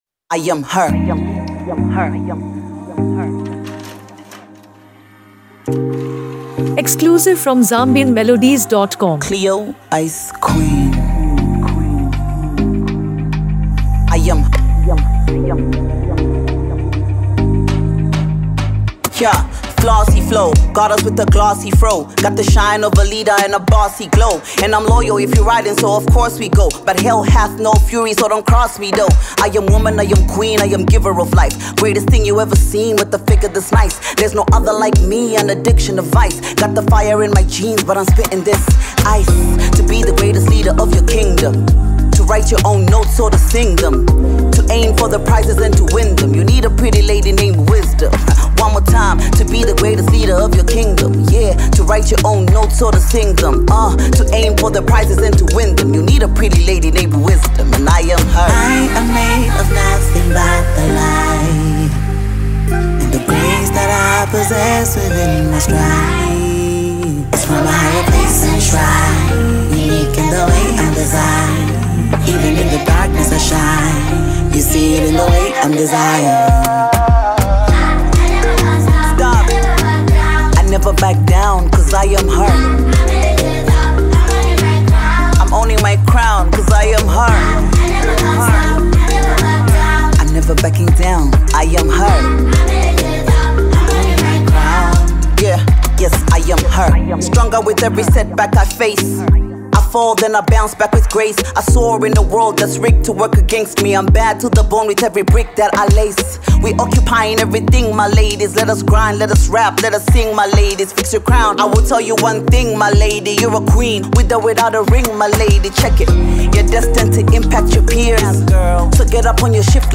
Zambian rap
Rooted in hip-hop with strong afro-fusion influences
commanding vocals over a polished beat
uplifting, meaningful music